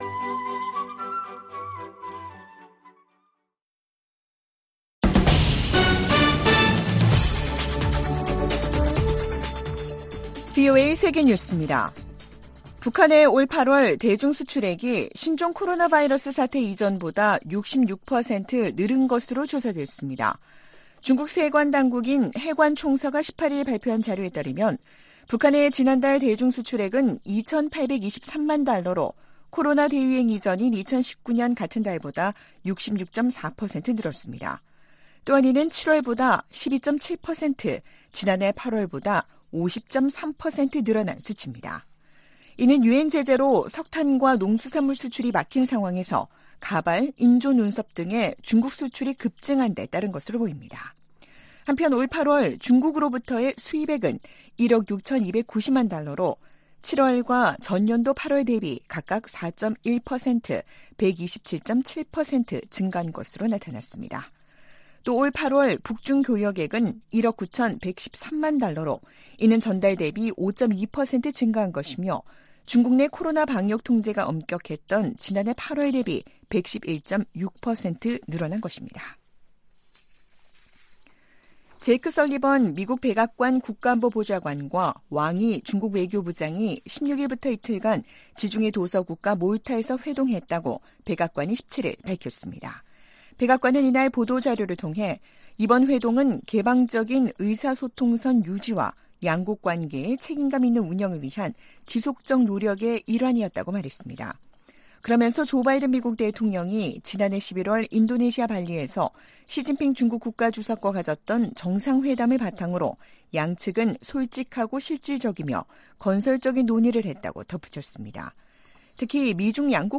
VOA 한국어 아침 뉴스 프로그램 '워싱턴 뉴스 광장' 2023년 9월 19일 방송입니다. 백악관은 북한과 러시아 사이에 무기 제공 논의가 계속 진전되고 있으며 예의 주시하고 있다고 밝혔습니다. 윤석열 한국 대통령은 북-러 군사협력 움직임에 대해 유엔 안보리 결의에 반한다며 한반도 문제 해결을 위한 중국의 역할을 거듭 촉구했습니다. 우크라이나 주변국들은 북한이 러시아에 우크라이나 전쟁에 필요한 무기를 제공 중이라는 주장에 촉각을 곤두세우고 있습니다.